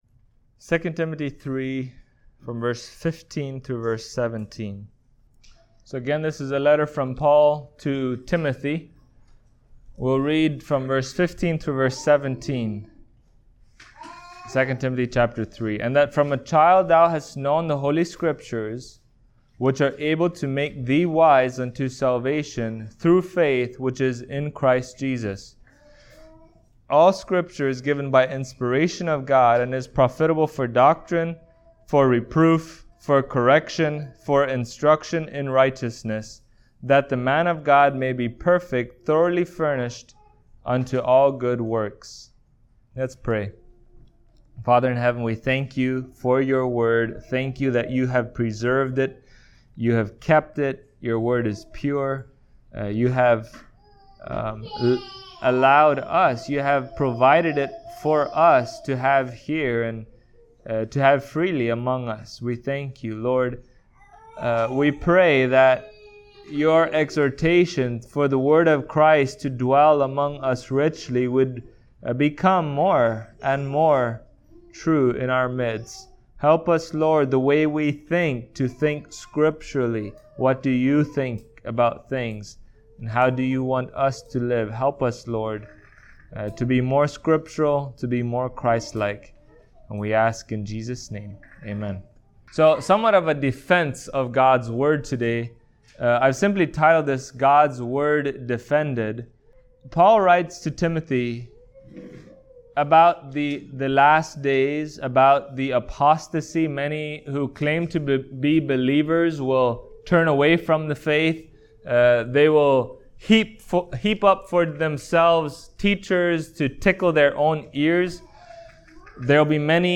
2 Timothy Passage: 2 Timothy 3:15-17 Service Type: Sunday Morning Topics